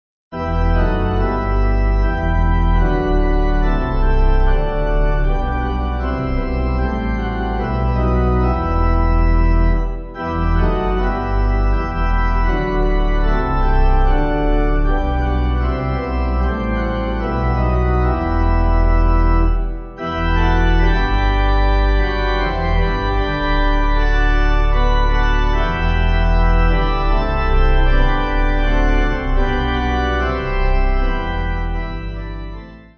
Organ
(CM)   4/Em